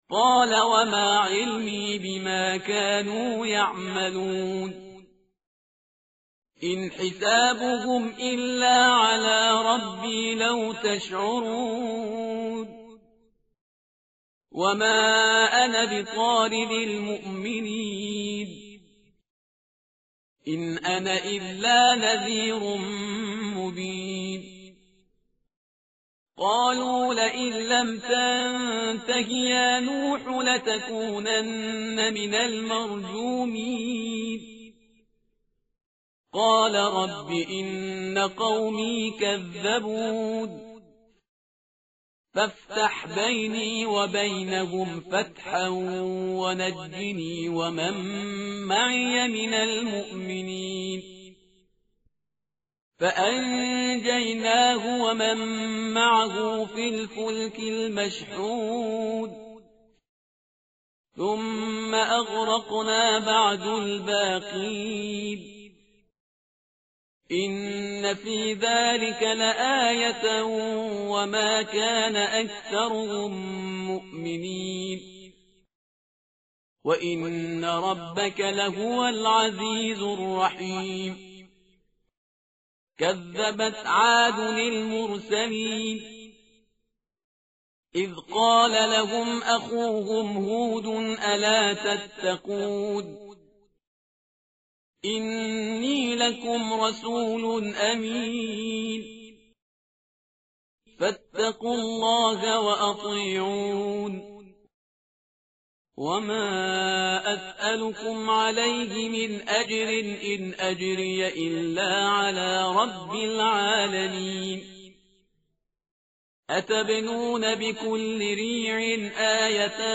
متن قرآن همراه باتلاوت قرآن و ترجمه
tartil_parhizgar_page_372.mp3